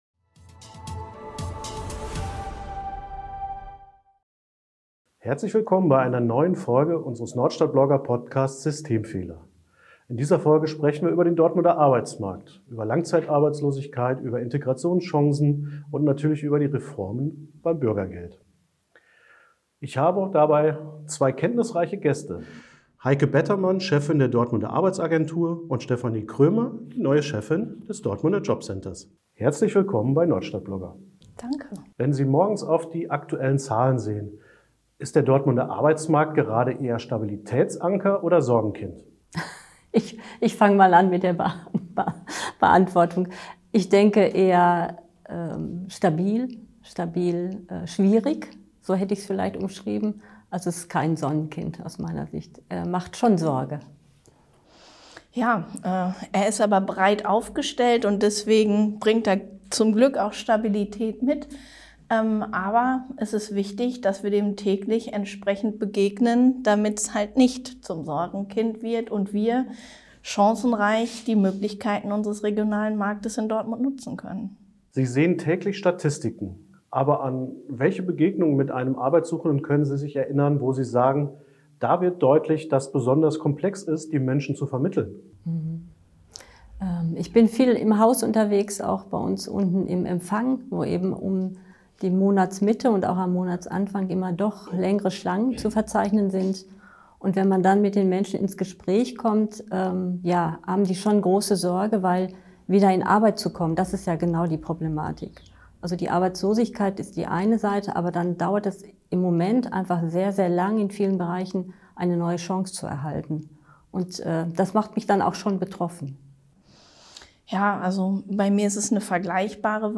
Das Gespräch macht deutlich, wie komplex die Lage ist – und warum einfache Antworten oft zu kurz greifen.